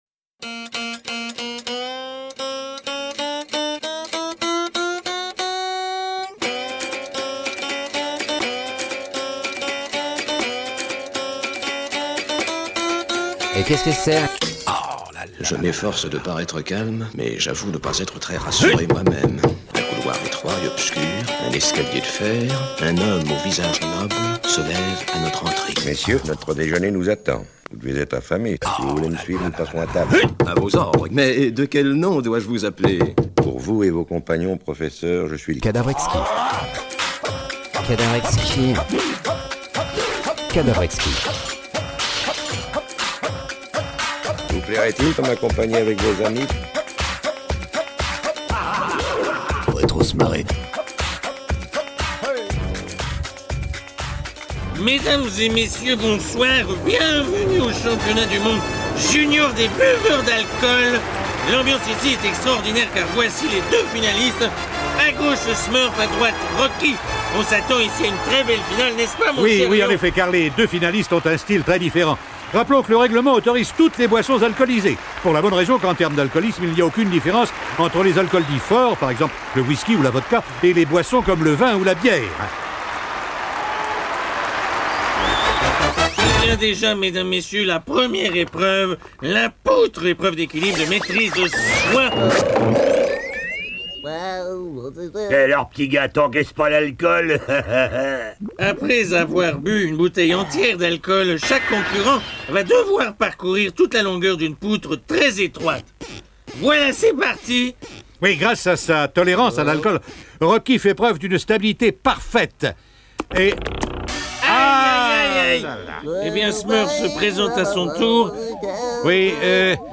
Cadavre-exquis, une émission surréaliste